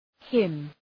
Προφορά
{hım}